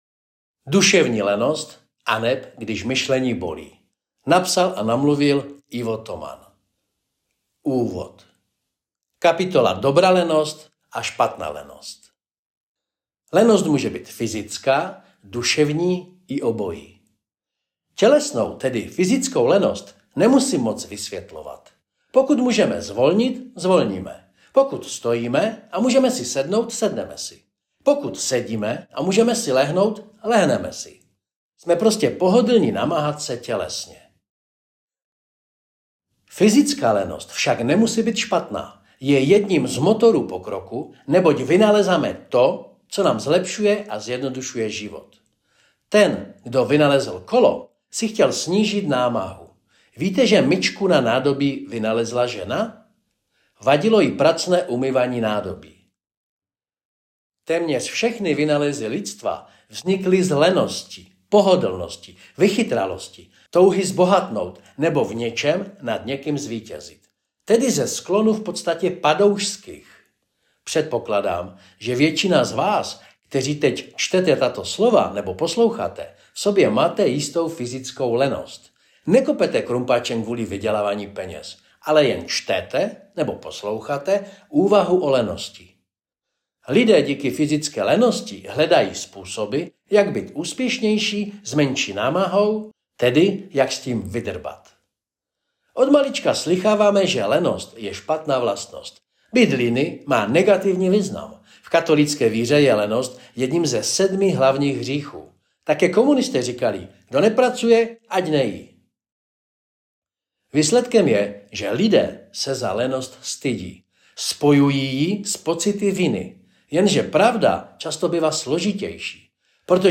Audio kniha Duševní lenost
Ukazka z audio knihy Dusevni lenost kapitola prvni Dobra lenost spatna lenost.mp3